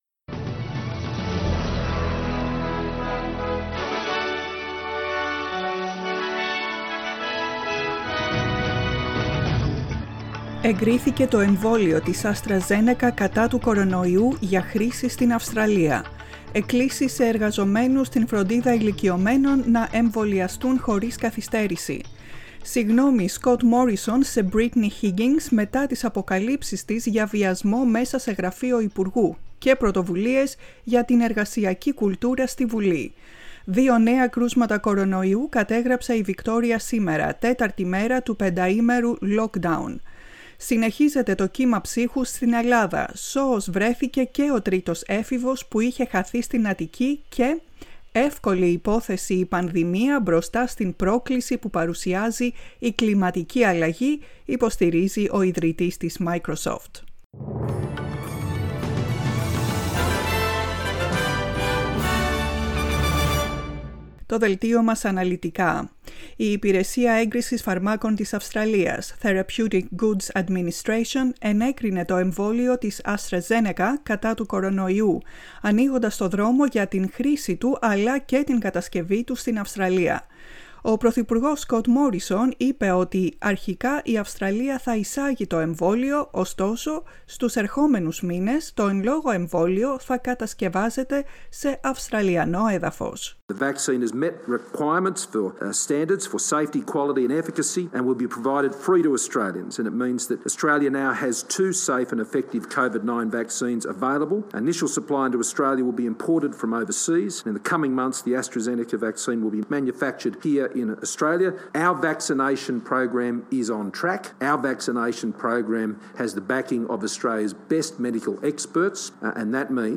Δελτίο Ειδήσεων στα Ελληνικά, 16.2.2021